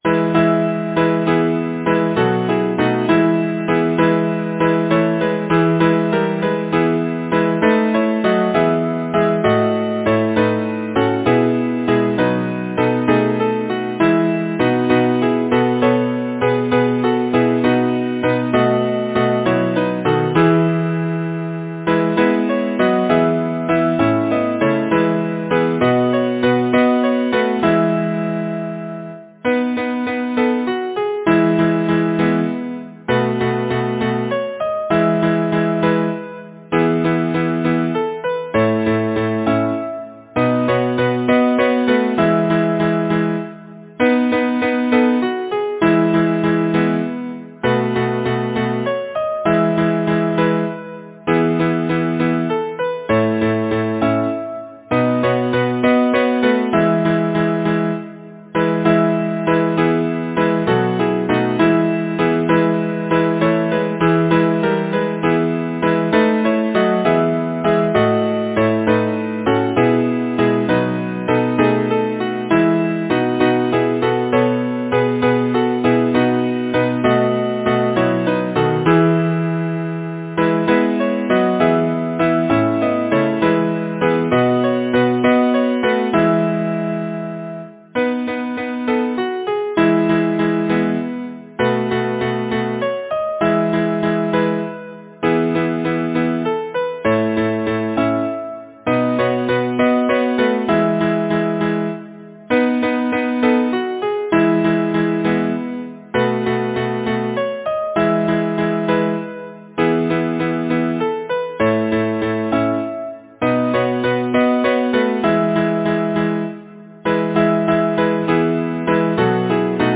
Title: Evening on the lake Composer: Lyman S. Leason Lyricist: H. H. Haydencreate page Number of voices: 4vv Voicing: SATB Genre: Secular, Partsong
Language: English Instruments: A cappella